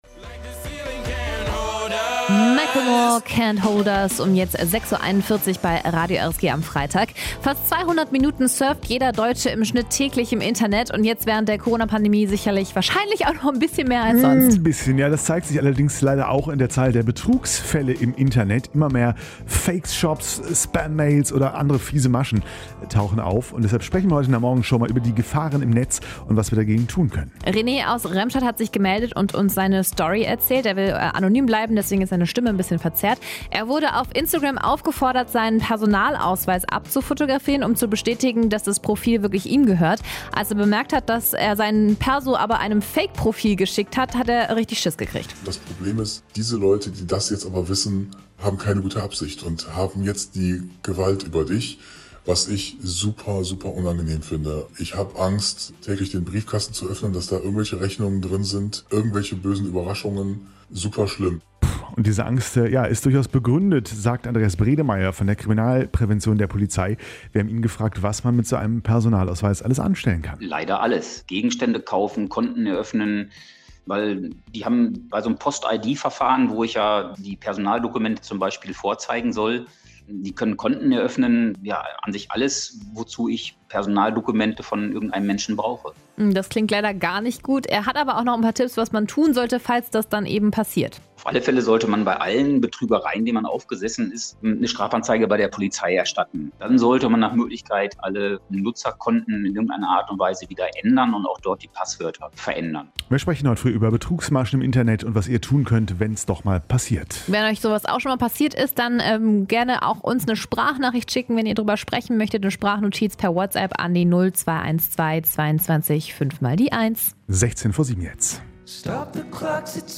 Spam-Mails, Fake-Profile, gehackte Konten, geklaute Identitäten: Immer mehr Menschen werden Opfer von Internetbetrügern und Cyberkrimenellen. Betroffene aus dem RSG-Land haben uns von ihren Erfahrungen berichtet.